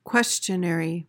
PRONUNCIATION:
(KWES-chuh-ner-ee)